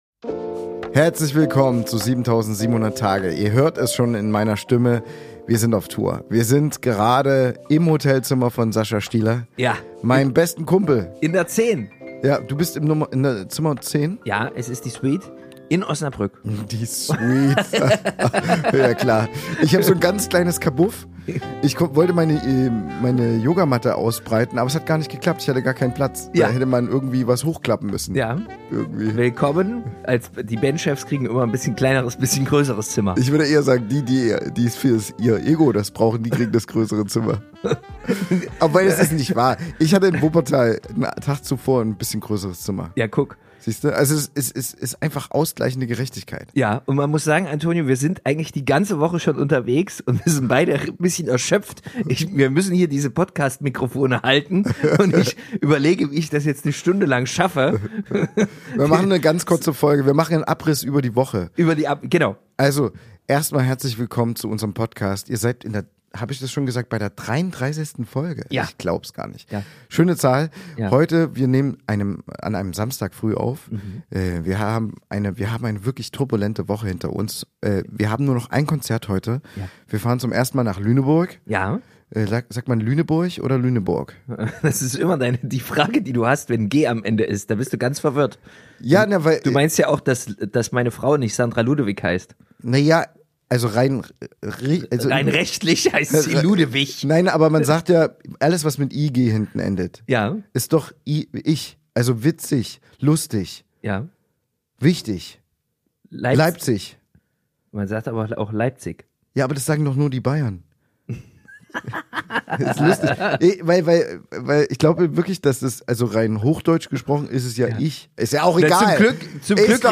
Beschreibung vor 1 Tag Wir sind auf Tour und melden uns diesmal direkt aus unserem Hotelzimmer in Osnabrück.